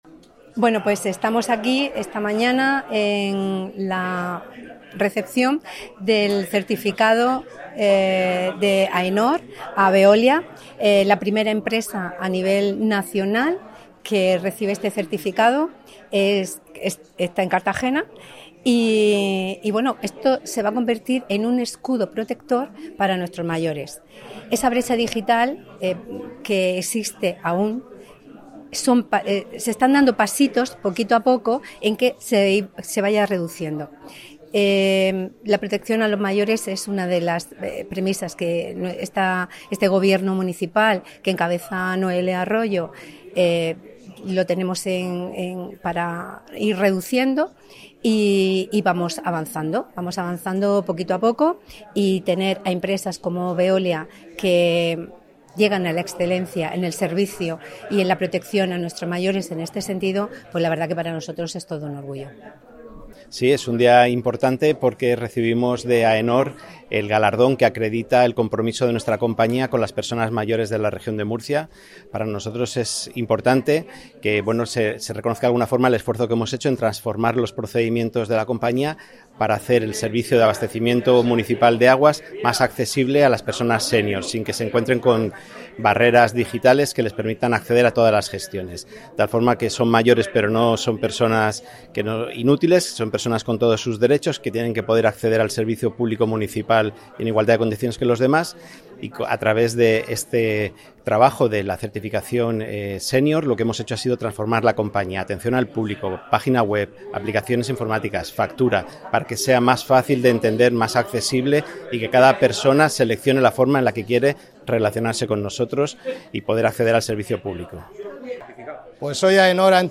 Declaraciones